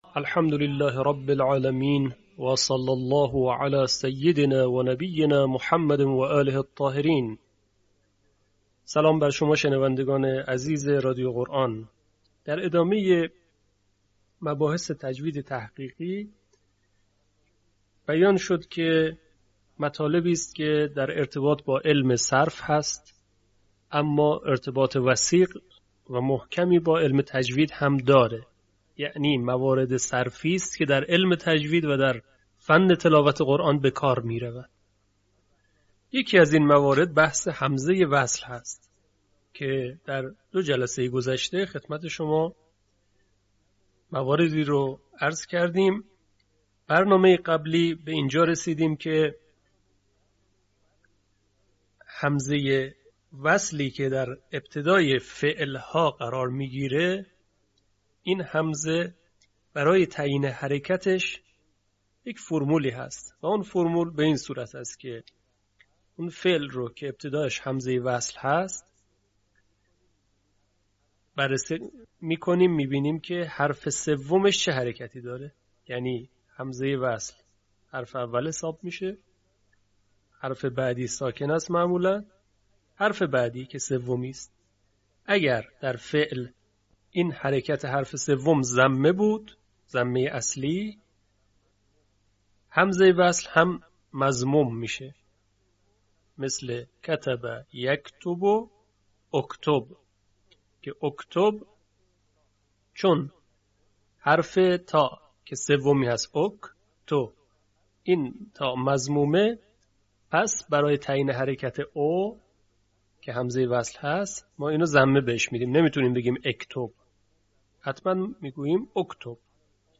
آموزش تجوید تحقیقی